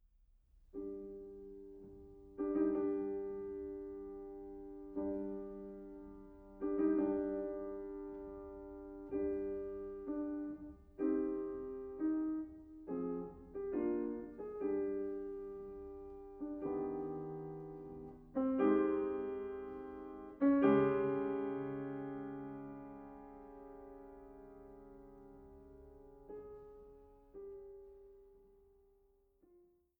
Mezzosopran
Klavier